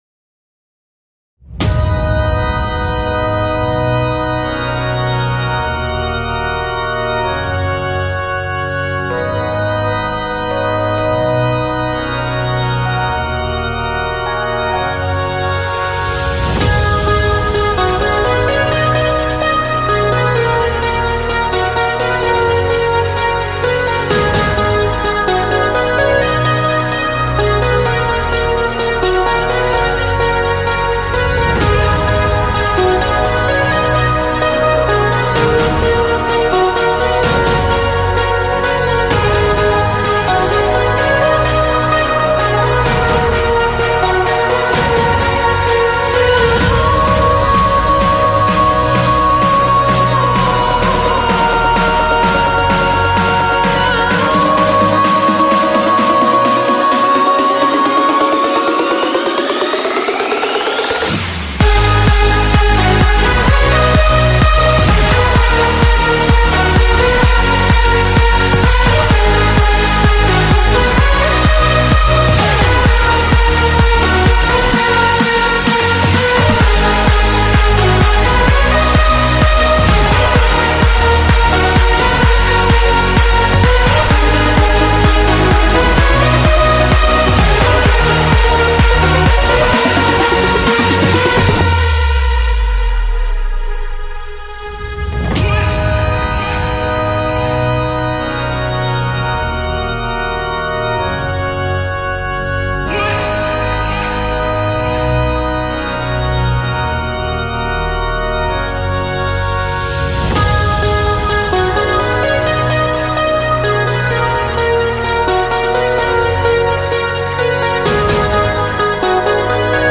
זה סקיצה לטראק הארדסטייל, ב150 BPM אז תהנו ותגיבו!